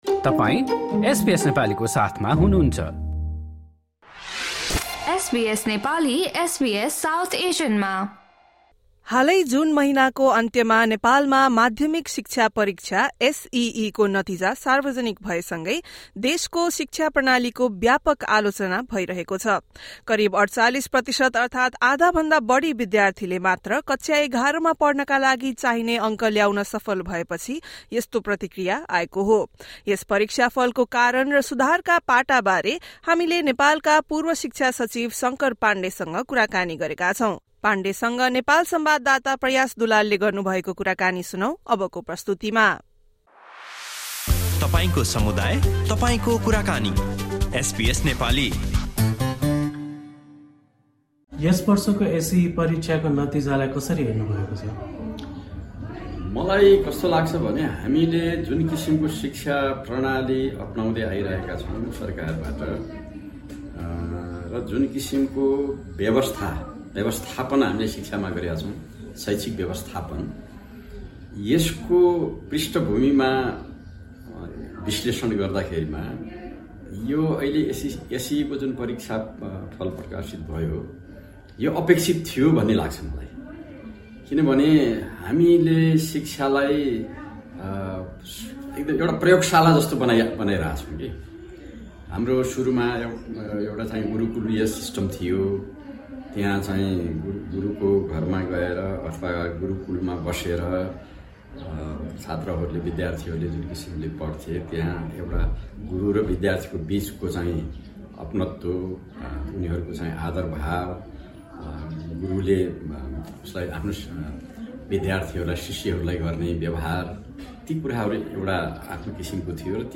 In Nepal, the 2023-24 Secondary Education Examination (SEE) results saw only around 48 per cent of students score passing marks. Former Education Secretary Shankar Pandey spoke to SBS Nepali about the yearly exam results and measures required for improvement in the education sector.